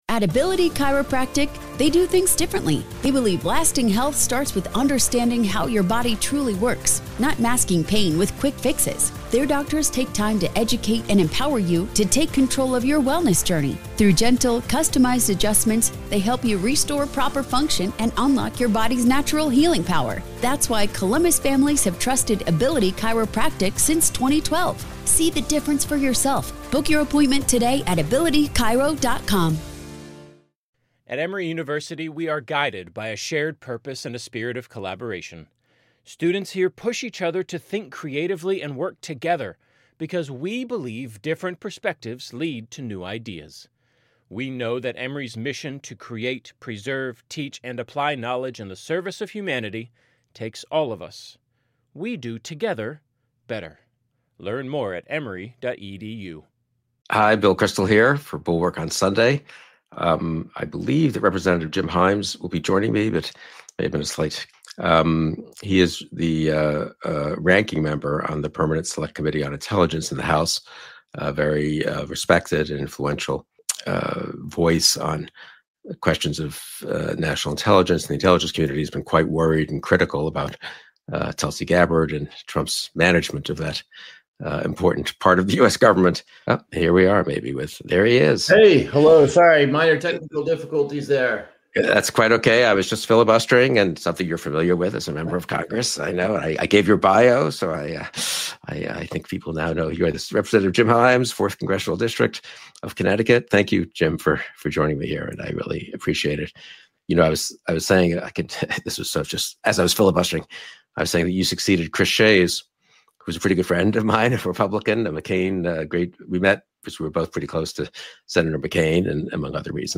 Bill Kristol talks with Rep. Jim Himes about Trump’s illegal boat strikes, Pentagon gag orders on the press, and how fear keeps Republicans silent as America edges toward authoritarianism.